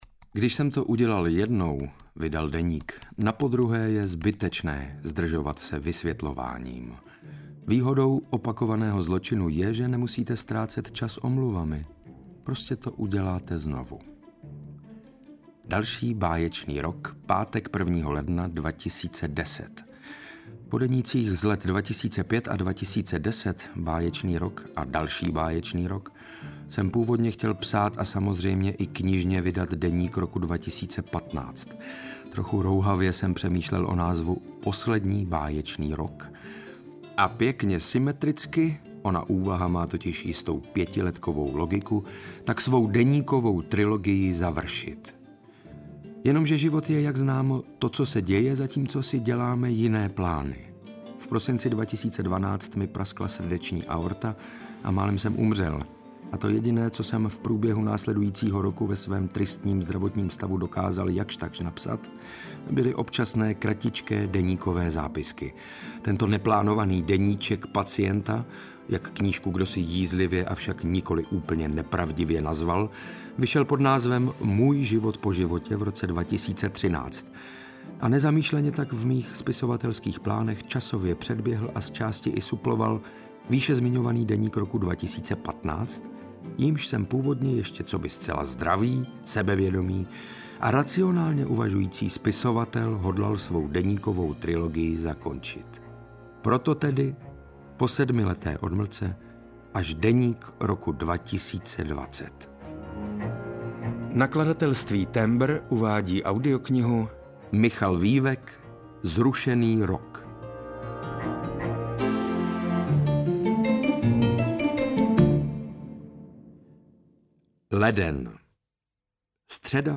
Zrušený rok – Deník 2020 audiokniha
Ukázka z knihy
• InterpretSaša Rašilov